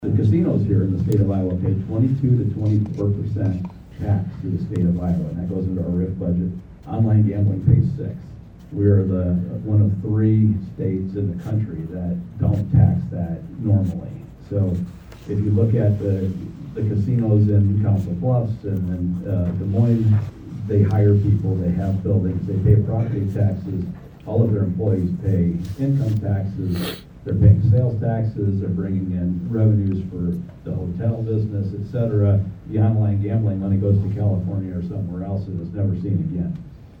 Pictured: Rep. Craig Williams (left) and Sen. Jason Schultz (right) speak during the Jan. 24 Legislative Forum at St. Anthony Regional Hospital